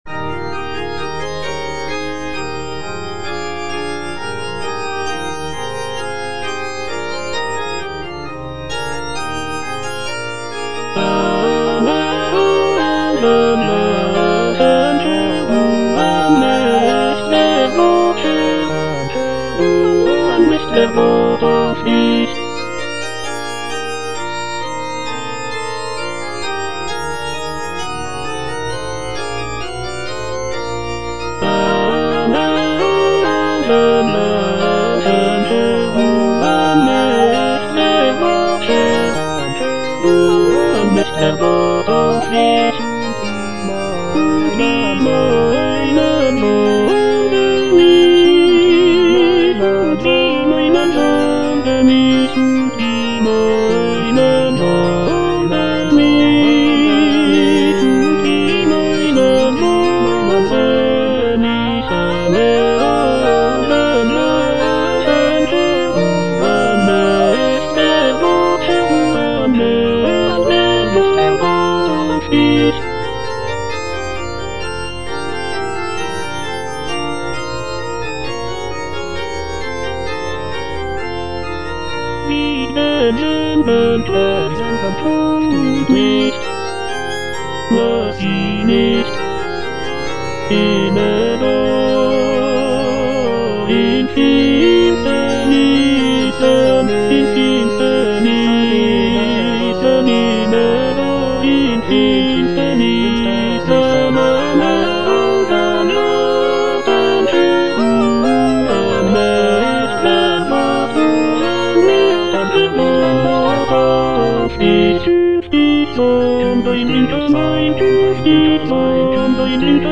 Choralplayer playing Cantata
The work features a festive opening chorus, expressive arias, and a lively final chorale.